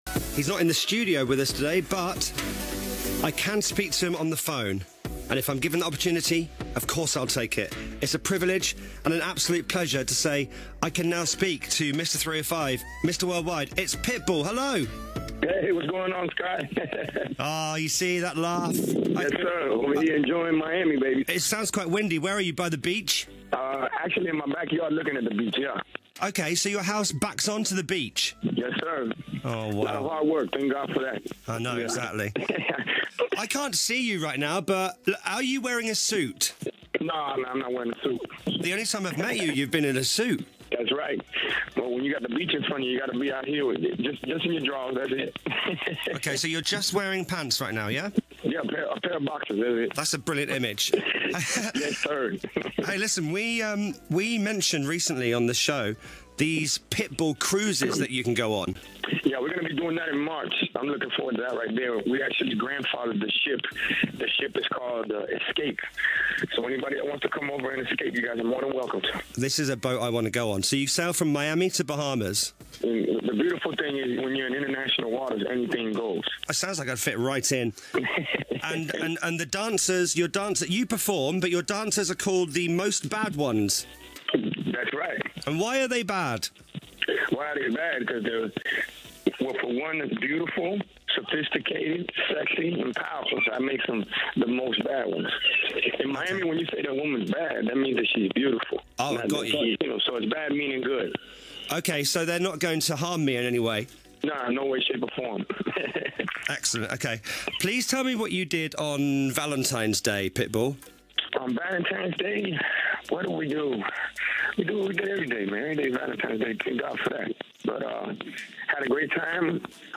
Pitbull called into BBC Radio 1 this morning to promote his new single, “Options”, his forthcoming album, “Climate Change”, the Pitbull Cruise, and more!